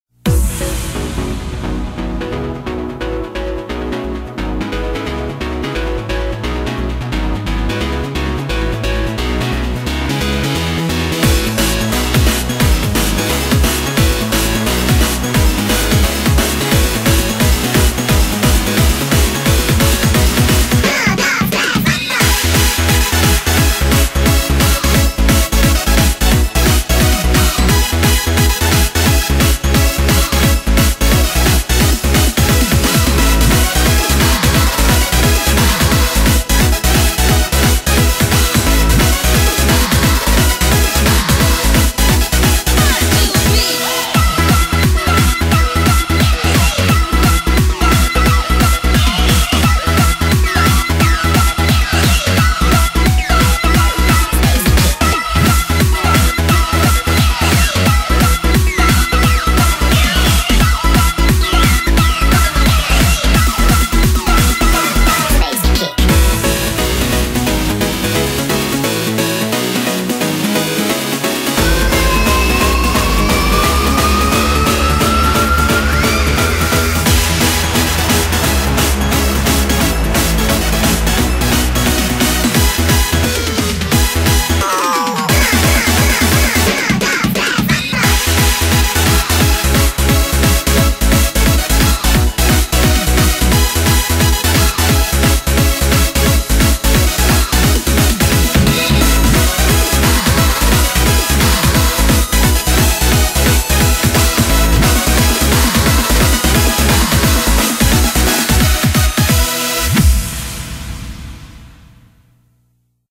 BPM175